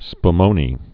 (sp-mōnē)